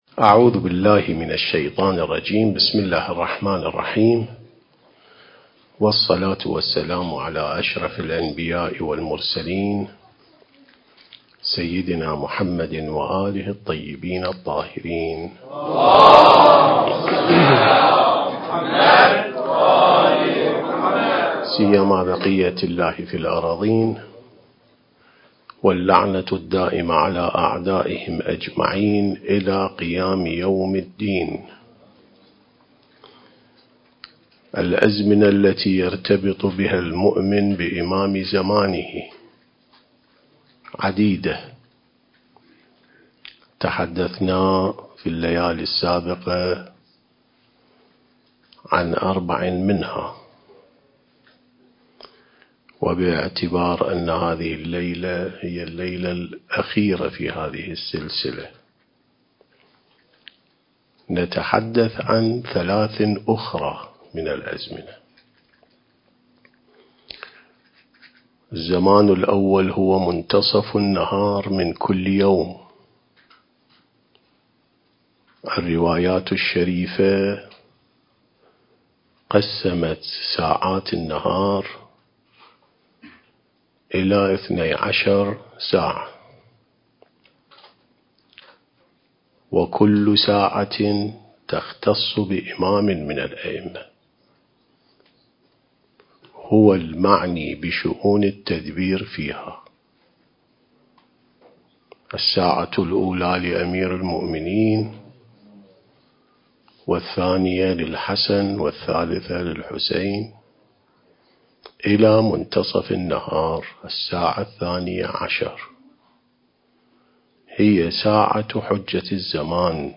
عين السماء ونهج الأنبياء سلسلة محاضرات: الارتباط بالإمام المهدي (عجّل الله فرجه)/ (7)